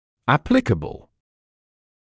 [ə’plɪkəbl], [‘æplɪkəbl][э’пликэбл], [‘эпликэбл]применимый; соответствующий, релевантный